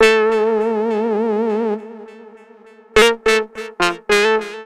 VOS SYNT 1-L.wav